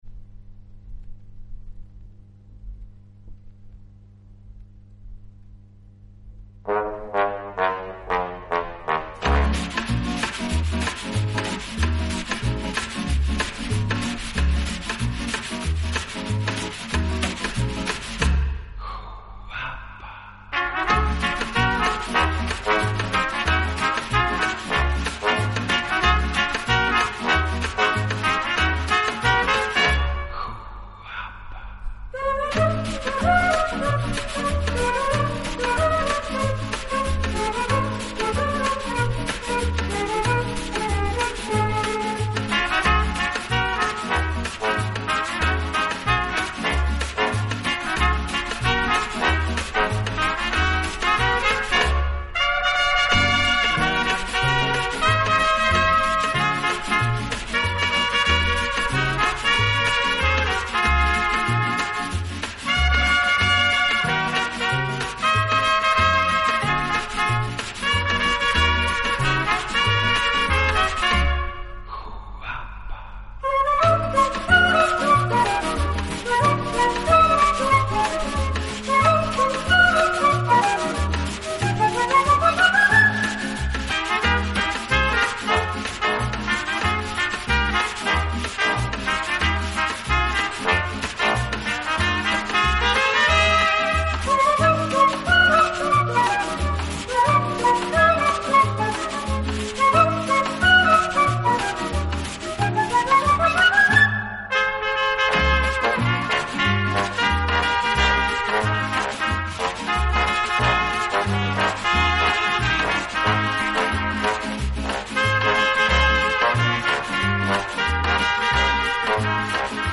【轻音乐】
以擅长演奏拉丁美洲音乐而著称。